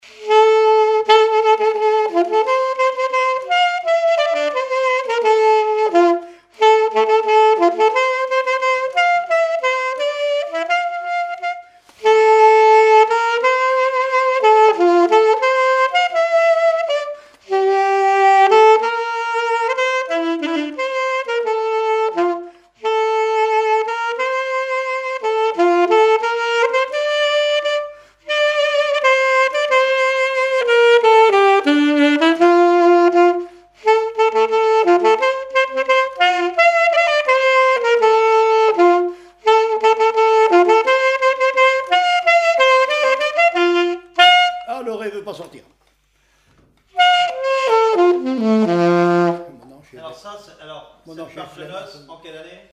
Mémoires et Patrimoines vivants - RaddO est une base de données d'archives iconographiques et sonores.
Marche de noce
témoignages et instrumentaux
Pièce musicale inédite